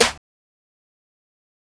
SouthSide Snare (32).wav